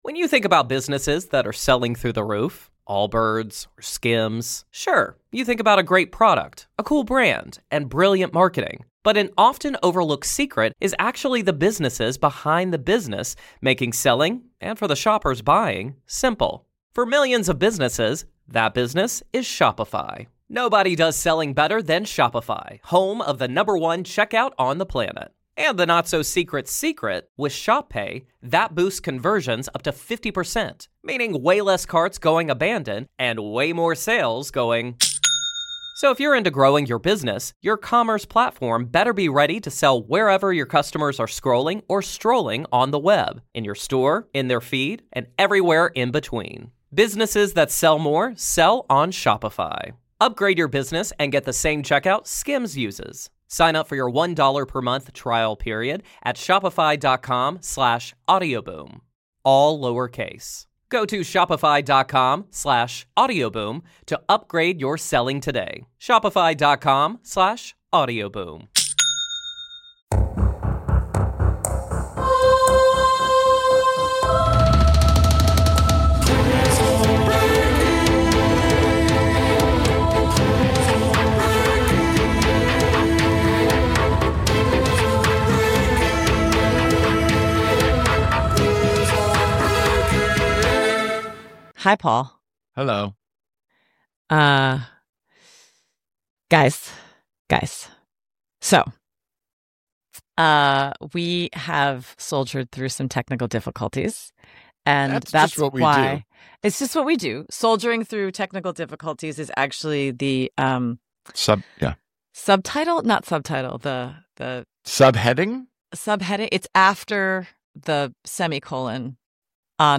This is Part 1 of two and a teaser of the full episode read which can be found on our Patreon.